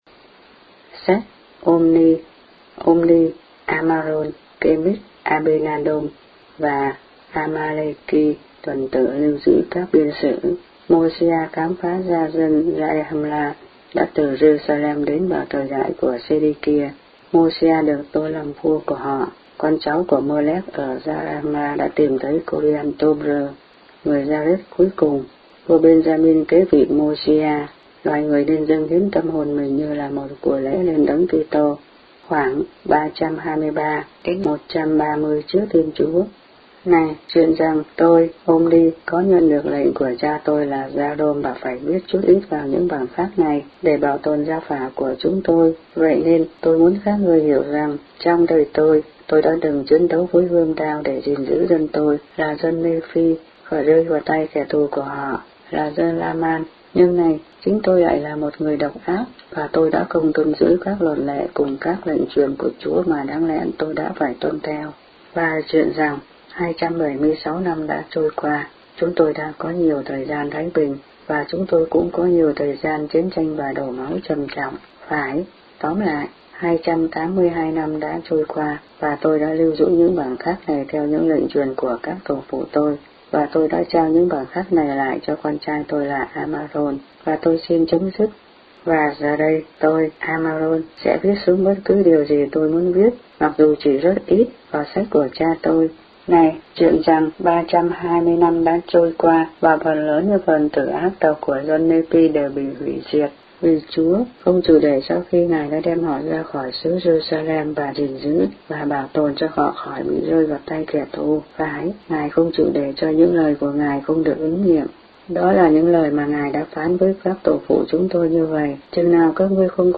The Book of Mormon read aloud in Vietnamese.